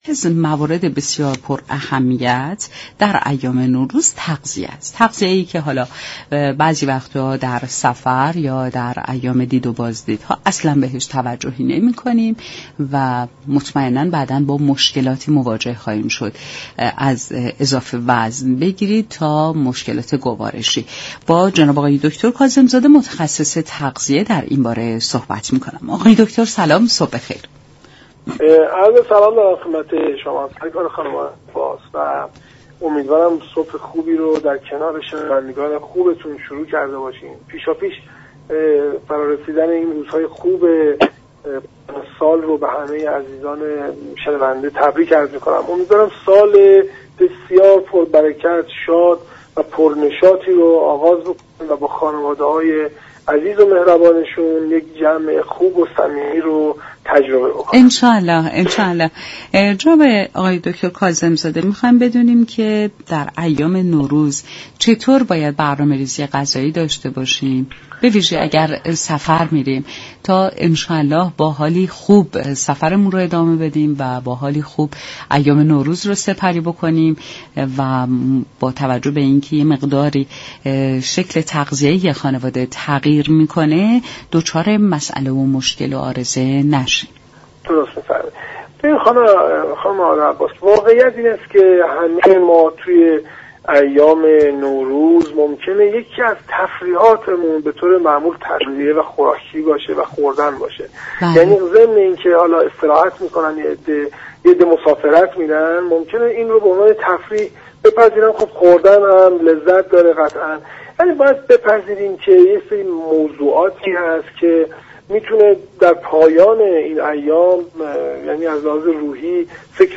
گفت وگو